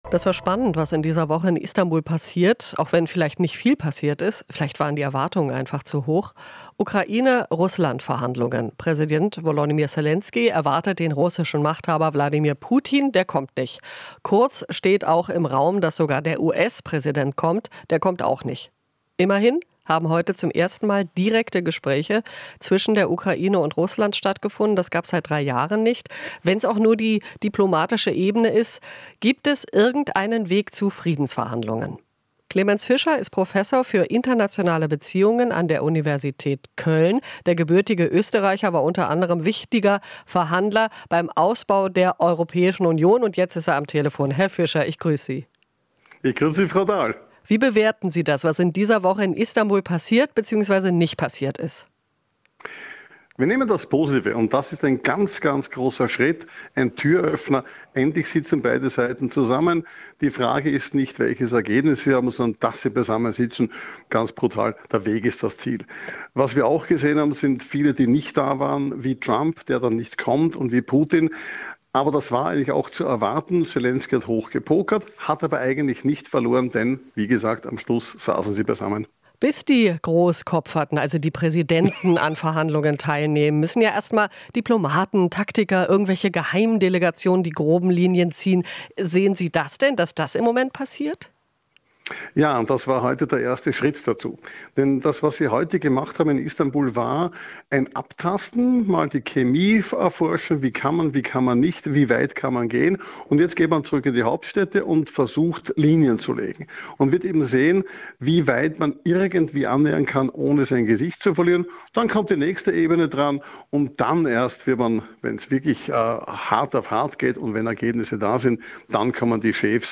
Interview - Sicherheitsexperte: Gespräche in Istanbul "ein sehr großer Schritt"